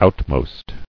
[out·most]